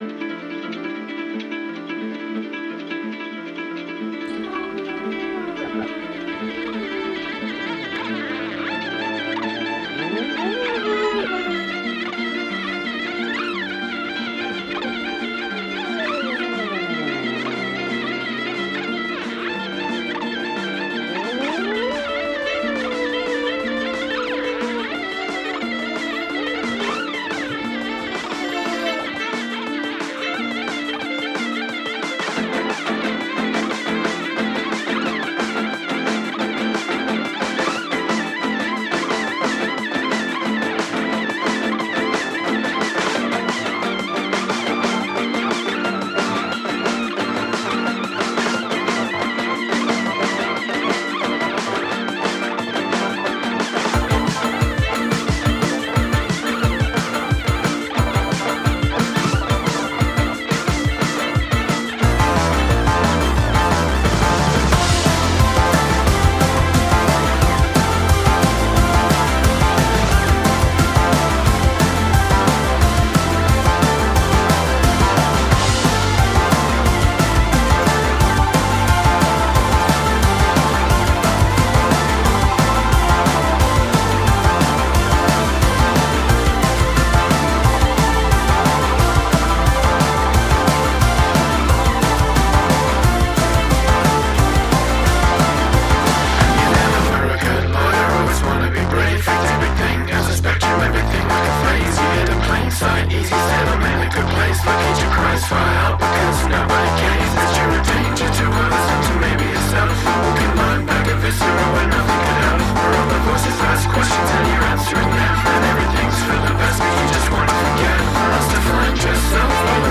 inventive, inspiring and downright danceable.